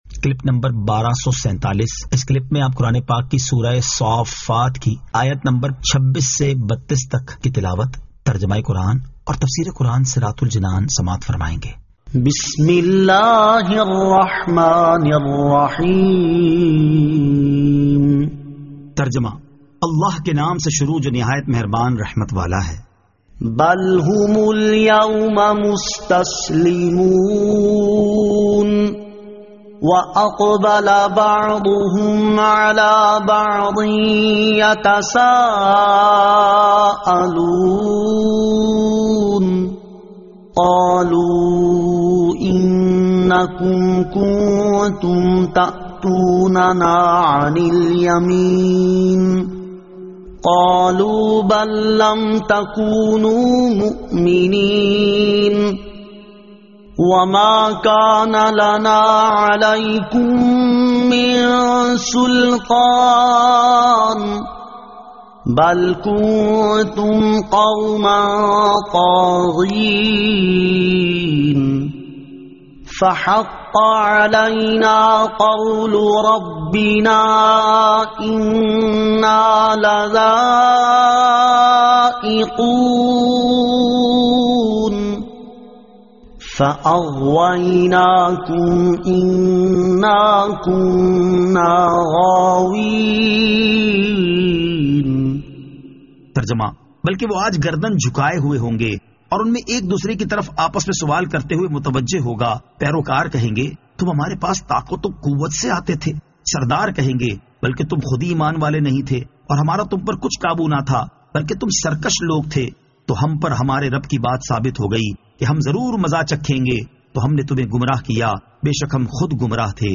Surah As-Saaffat 26 To 32 Tilawat , Tarjama , Tafseer